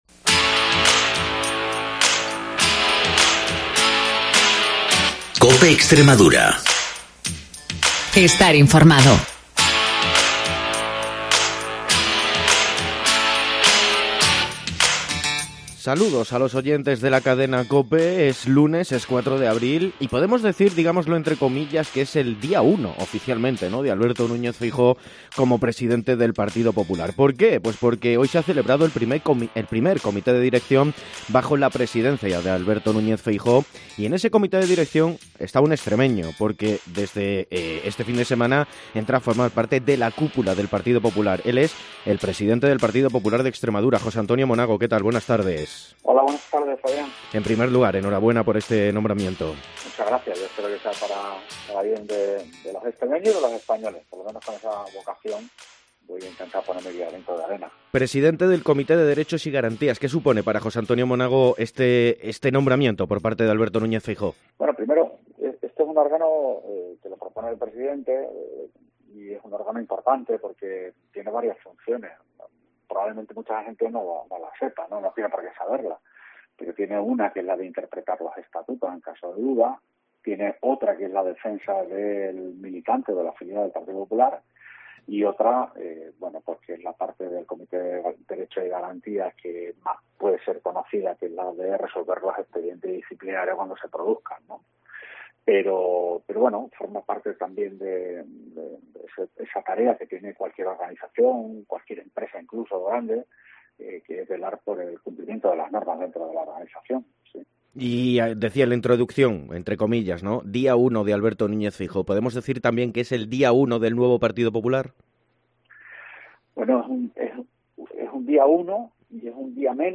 Primera entrevista de José A. Monago tras ser designado miembro del comité de dirección del PP de Feijóo